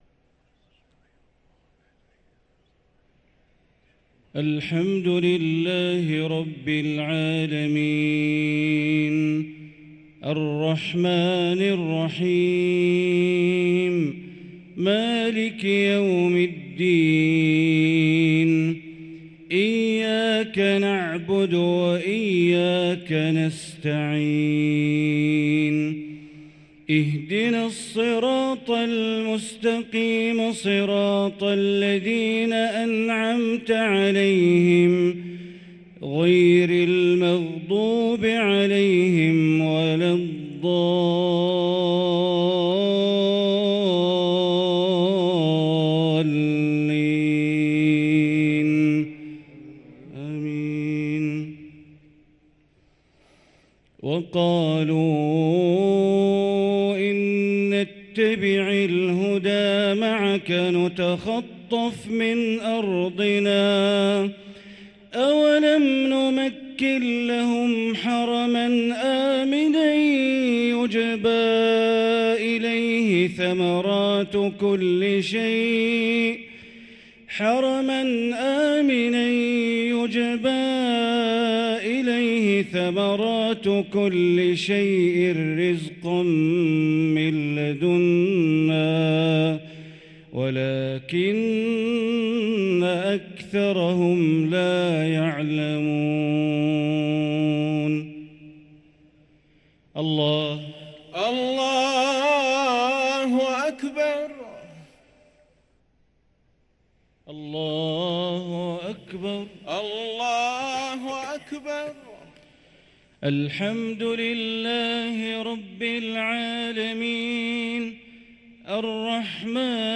صلاة العشاء للقارئ بندر بليلة 23 رمضان 1444 هـ
تِلَاوَات الْحَرَمَيْن .